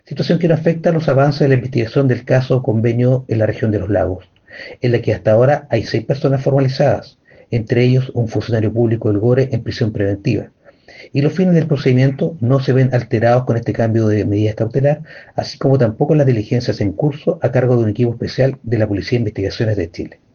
En una declaración, el aludido Fiscal Marco Muñoz, que encabeza la investigación del Caso Convenios en Los Lagos, se limitó a plantear que este cambio de cautelar no afecta las investigaciones en curso.